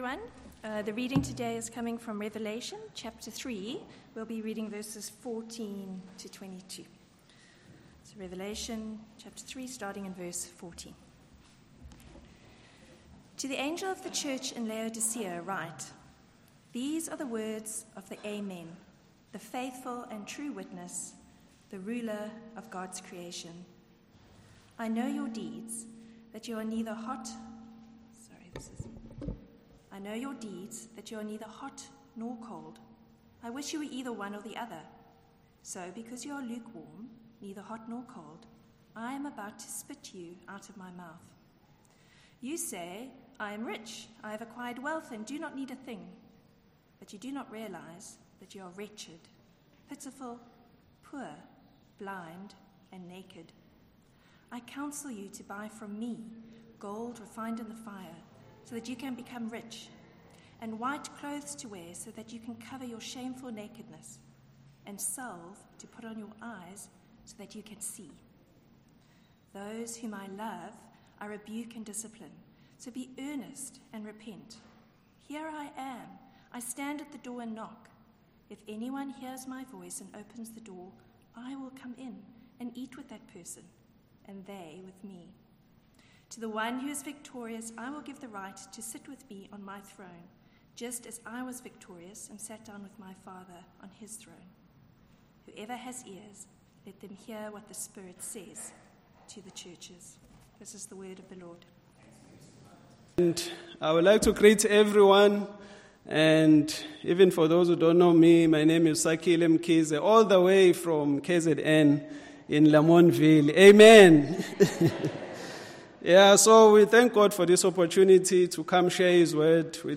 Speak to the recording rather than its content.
Revelation Passage: Revelation 3:14-22 Service Type: Morning Service « Two Women